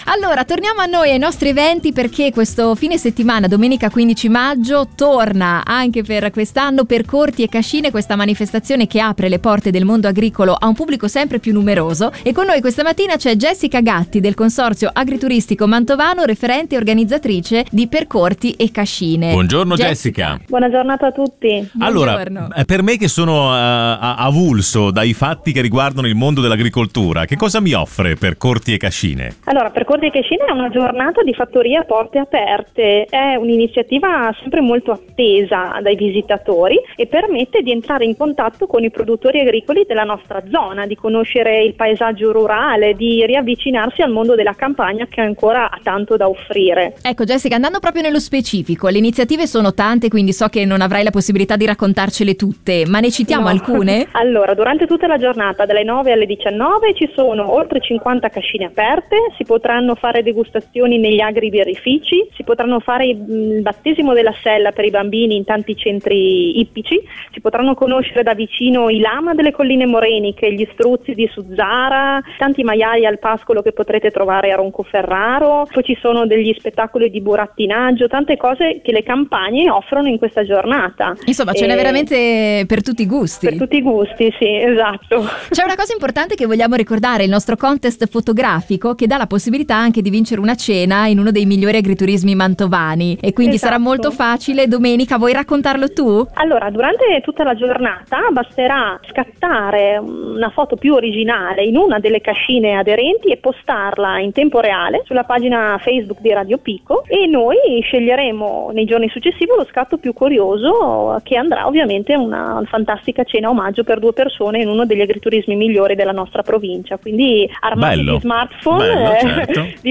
intervenuta nel corso di Passepartout